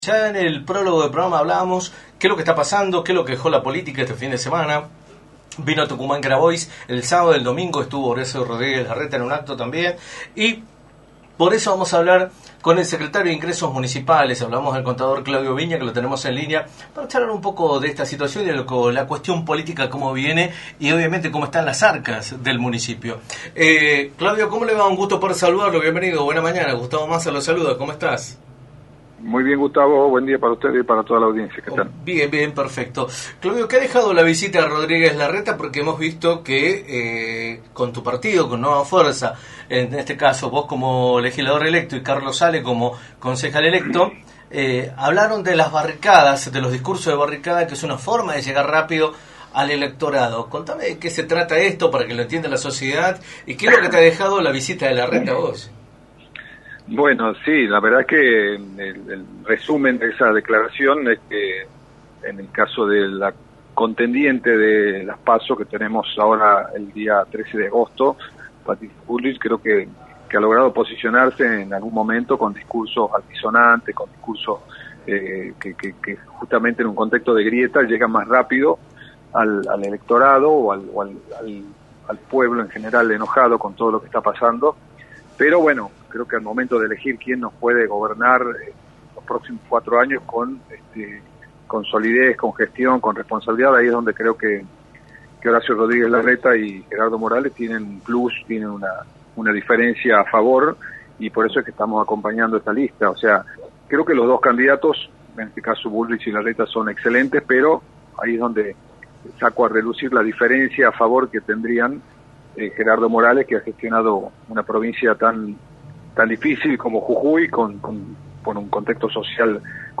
Claudio Viña, Secretario de Ingresos Municipales de San Miguel de Tucumán y Legislador electo, informó en Radio del Plata Tucumán, por la 93.9, las repercusiones de la visita del precandidato a Presidente por Juntos por el Cambio, Horacio Rodríguez Larreta.